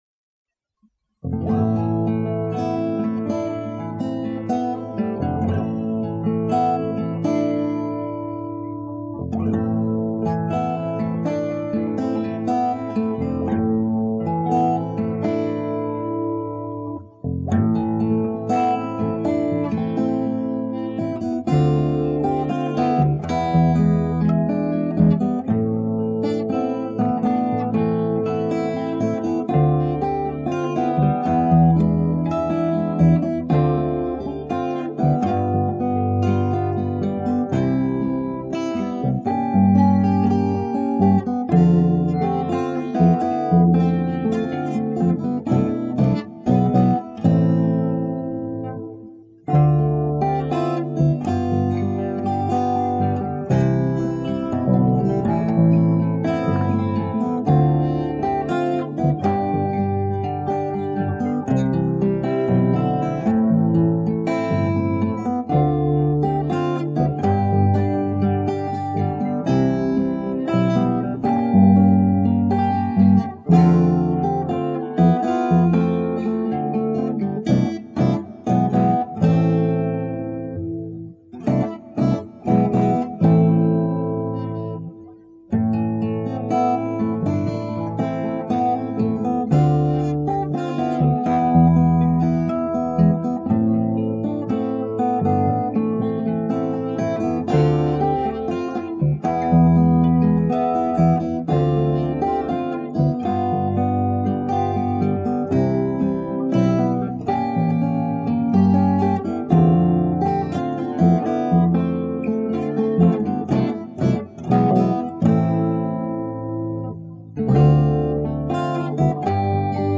Accoustic minus